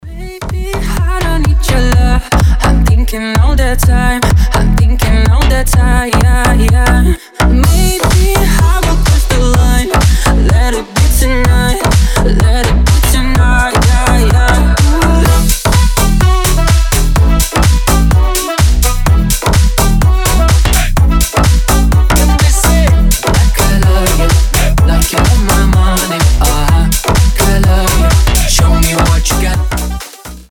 зажигательные
Классный заводной ремикс